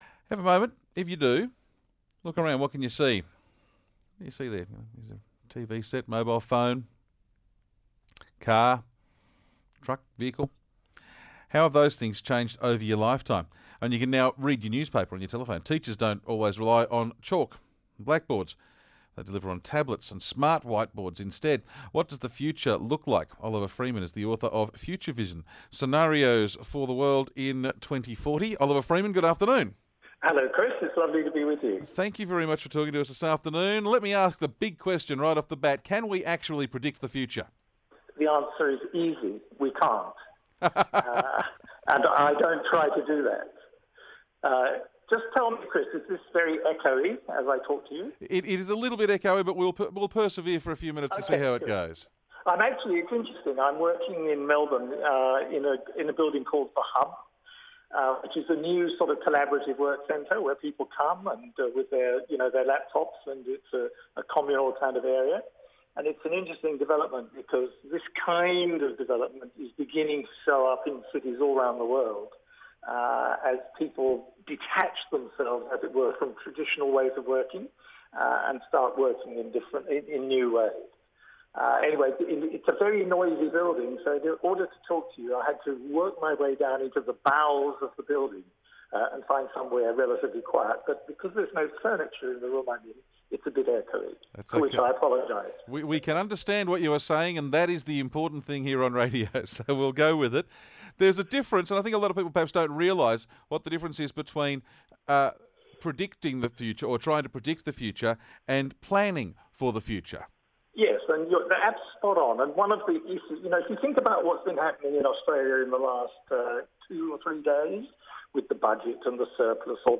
ABC Interview